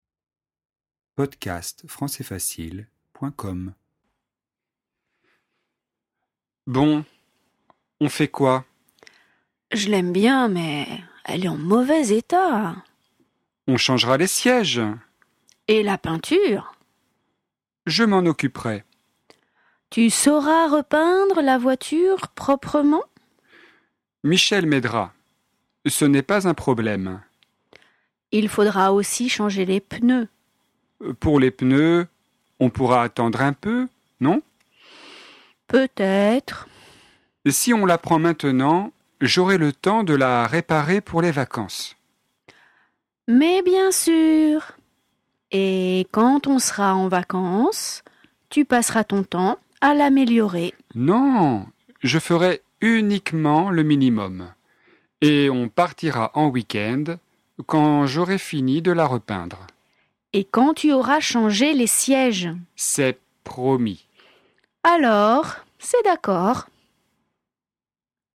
DIALOGUE : La voiture d’occasion